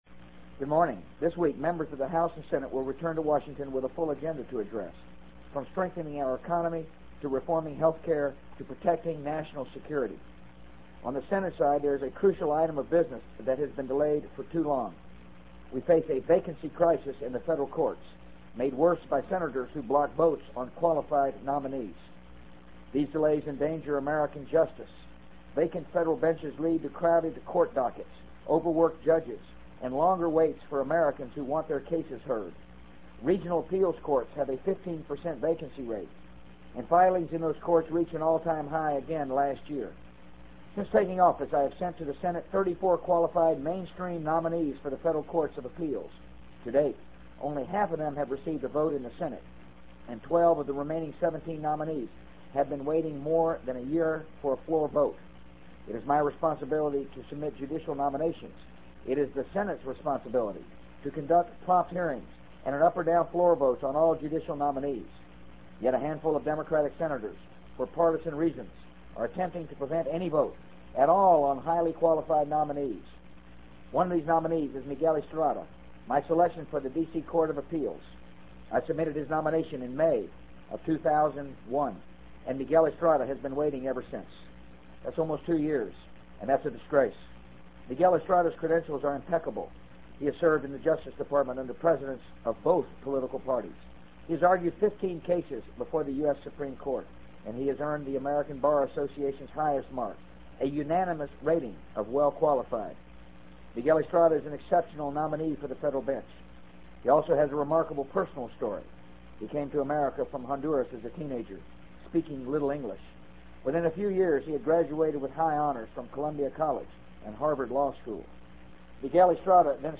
【美国总统George W. Bush电台演讲】2003-02-22 听力文件下载—在线英语听力室